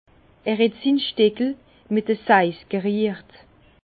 Bas Rhin
Ville Prononciation 67
Herrlisheim